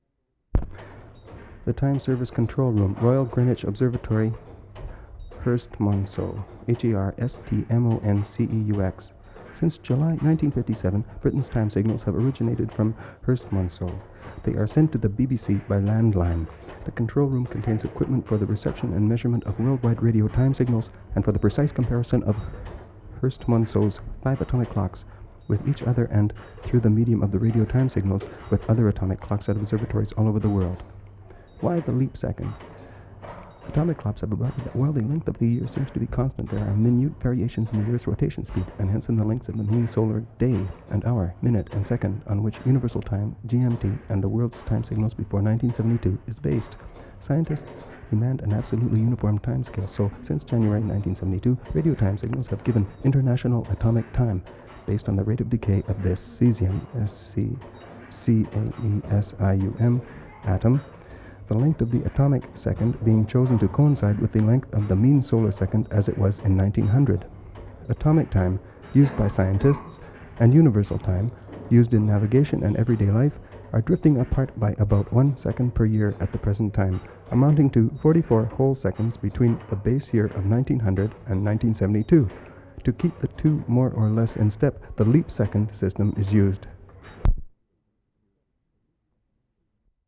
WORLD SOUNDSCAPE PROJECT TAPE LIBRARY
CONTACT RECORDING OF 24 HOUR CLOCK, at entrance to Greenwich observatory.
6. & 7. Outdoor ambience of people, birds in combination with regular ticking of clock.